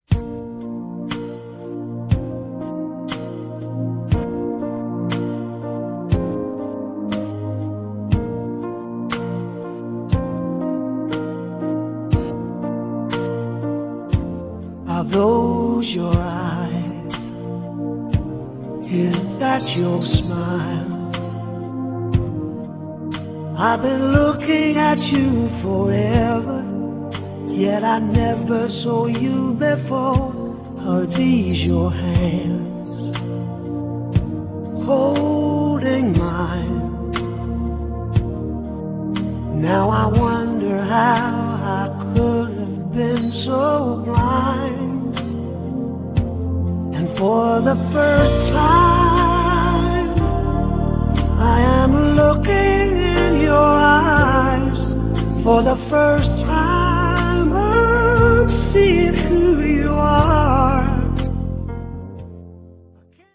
那独特的沙哑嗓音，使歌曲充满原始，自然的韵味。